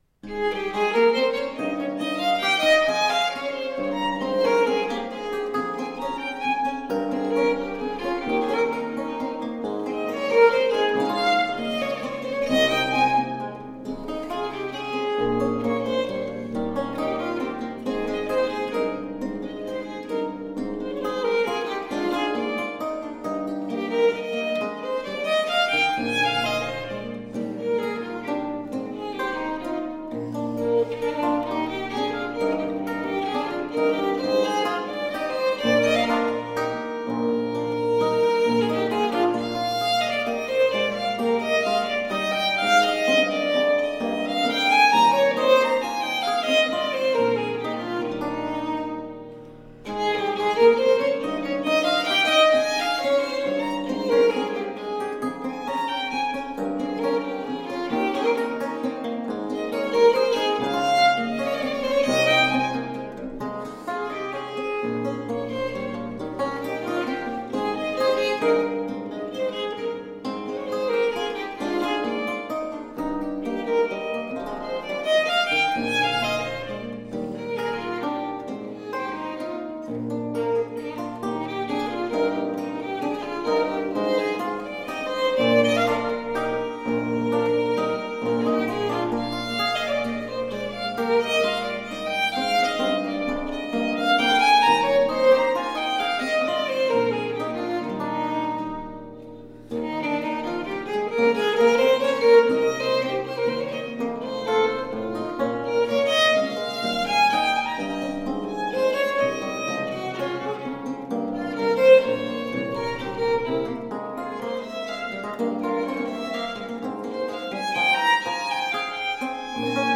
A feast of baroque lute.
Tagged as: Classical, Baroque, Instrumental, Lute